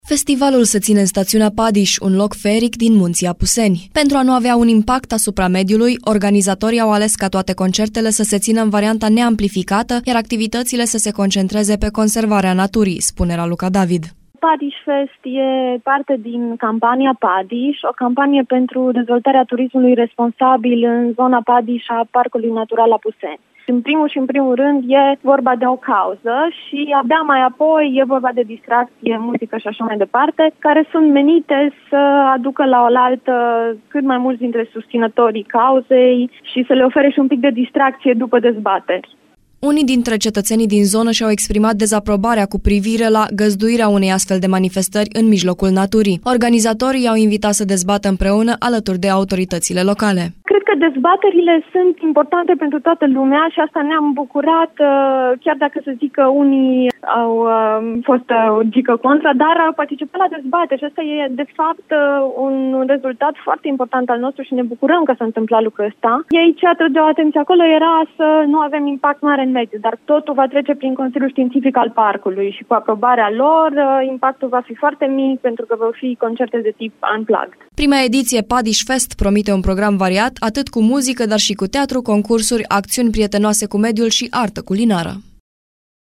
în dialog cu